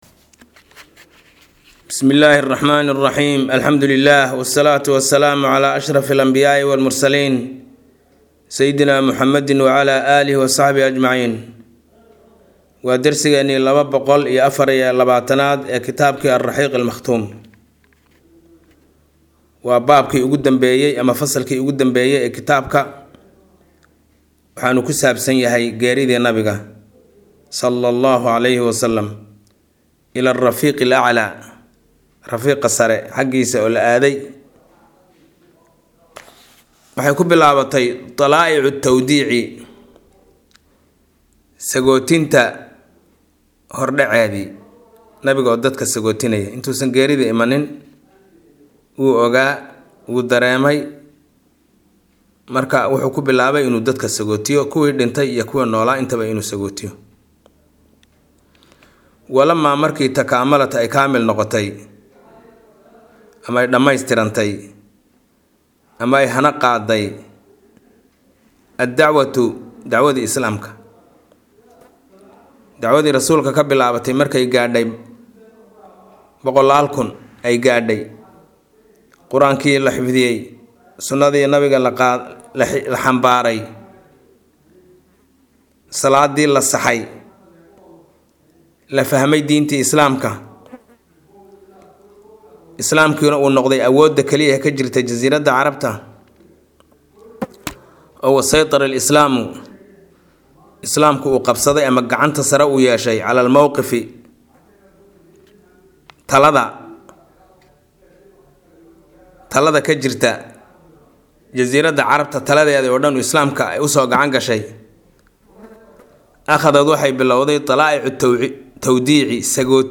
Maqal– Raxiiqul Makhtuum – Casharka 224aad